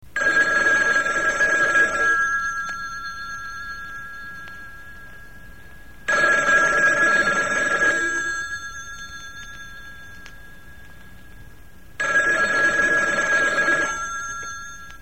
ringing-telephone_24732.mp3